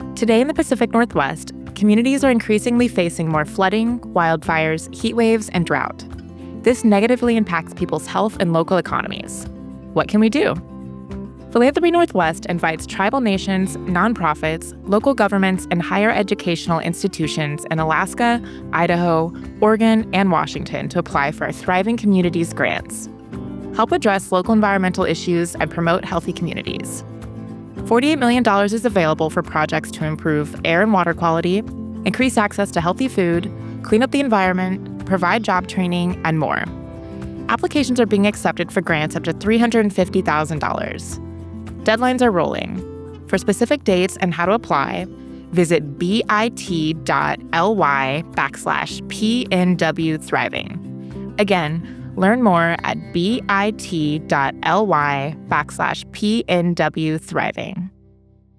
60_sec_pnw-thriving-communities-psa.wav